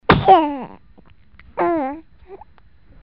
婴儿打喷嚏音效_人物音效音效配乐_免费素材下载_提案神器
婴儿打喷嚏音效免费音频素材下载